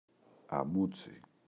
αμούτσι [aꞋmutsi]